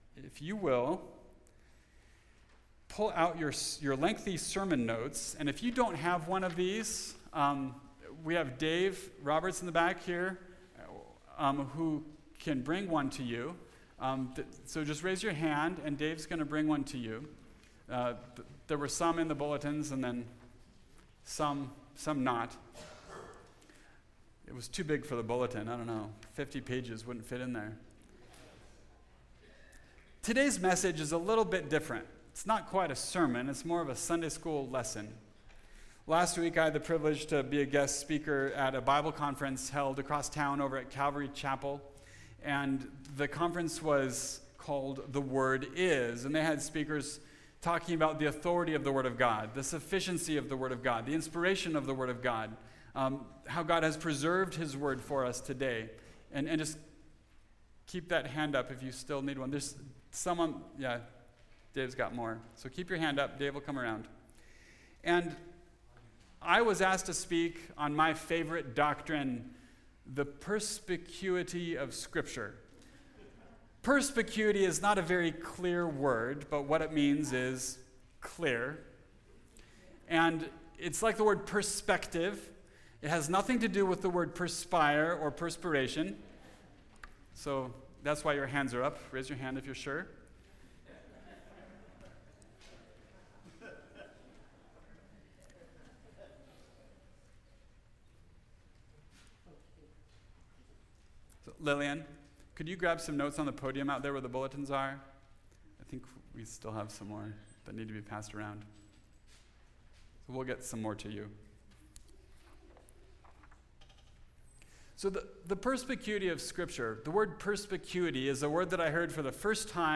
Perspecuity Presentation – The Word is Clear – Mountain View Baptist Church
Topical Message